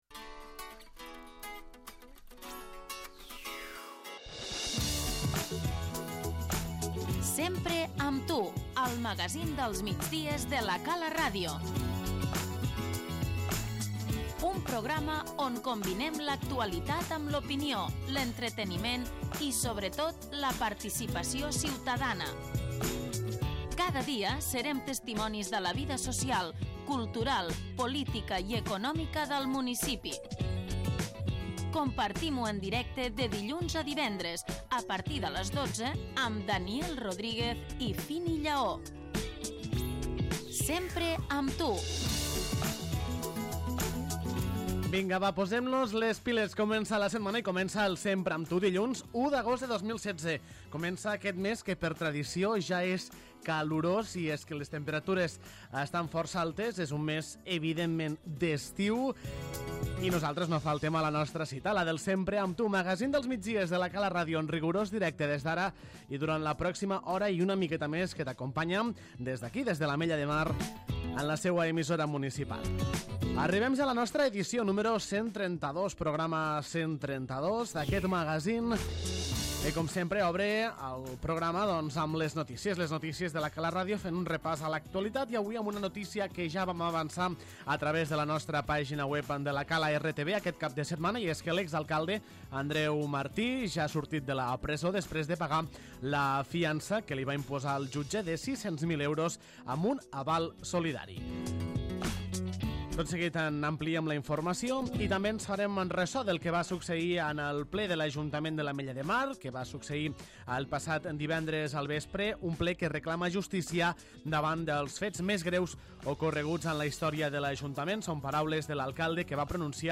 Comença el mes d'agost i torna el magazín dels migdies de La Cala Ràdio, el Sempre amb tu.